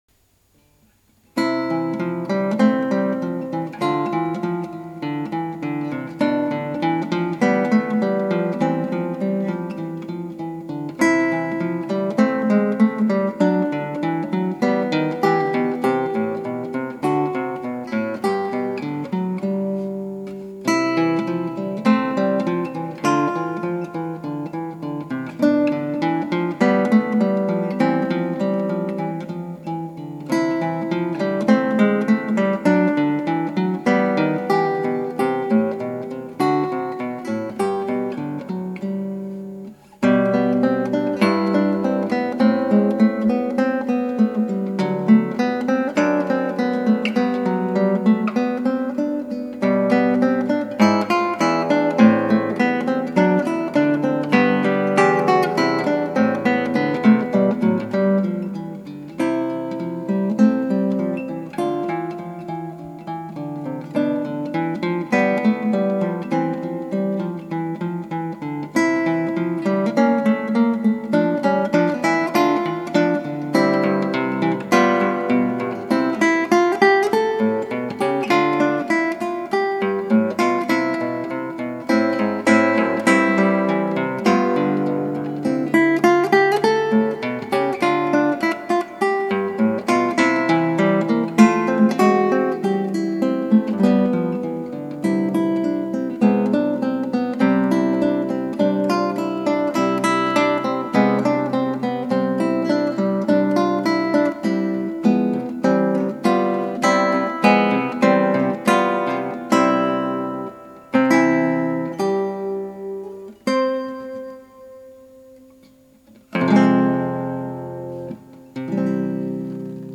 クラシックギター 「幼い頃・・・」 - 「エチュード6」Etude 6
ギターの自演をストリーミングで提供